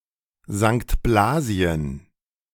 St. Blasien (German pronunciation: [zaŋkt ˈblaːziən]